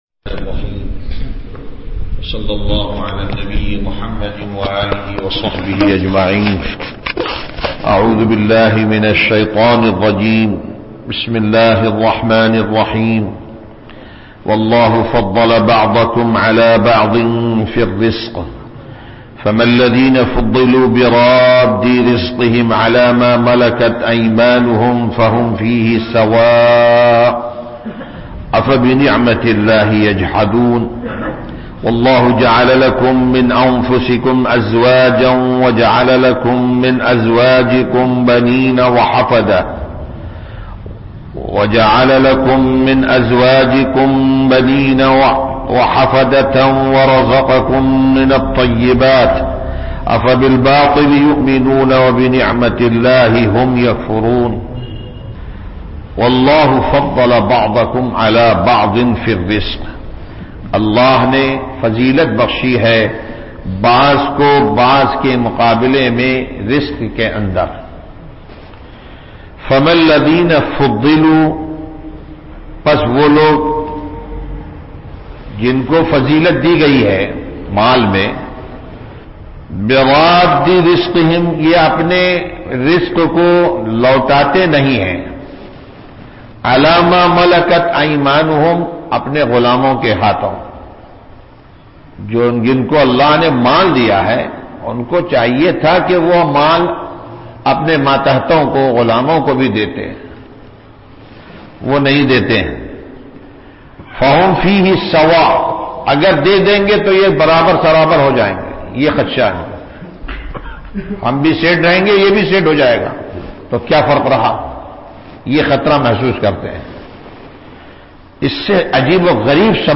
درس قرآن نمبر 1194
درس-قرآن-نمبر-1194.mp3